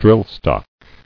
[drill·stock]